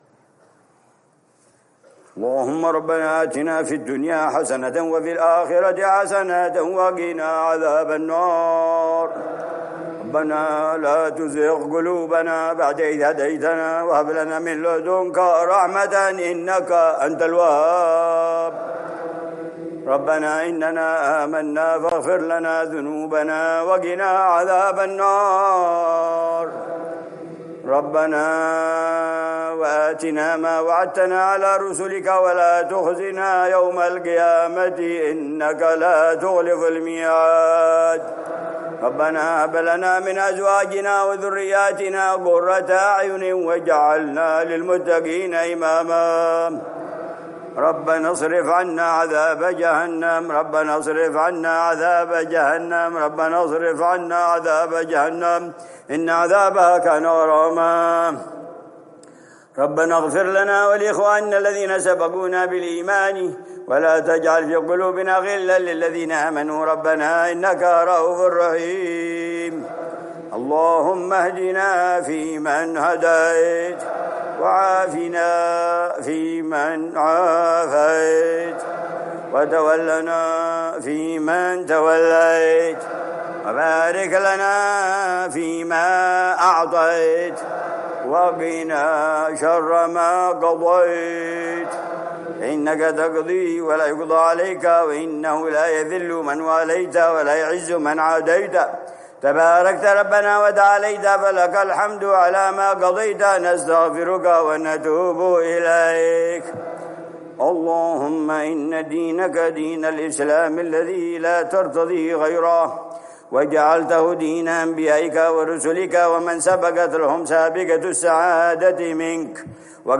دعاء العلامة الحبيب عمر بن حفيظ في قنوت الوتر، ليلة الأحد 16 رمضان 1446هـ ( الإسلام )